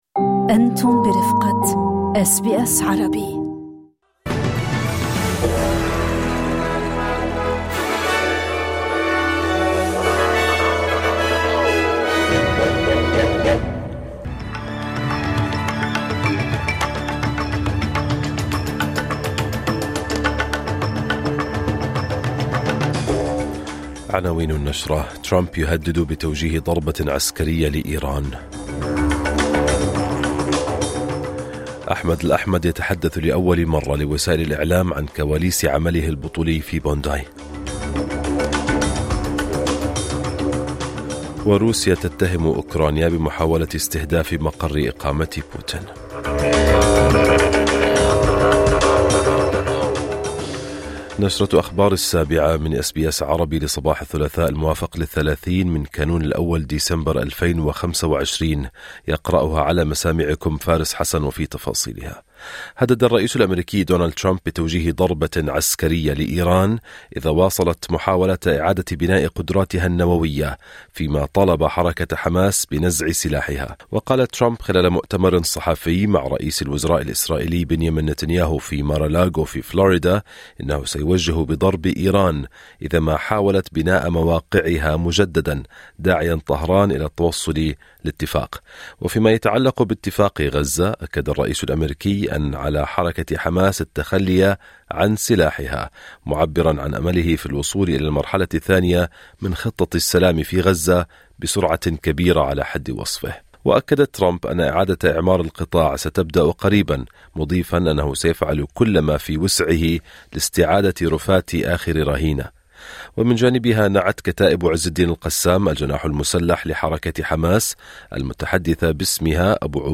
نشرة أخبار الصباح 30/12/2025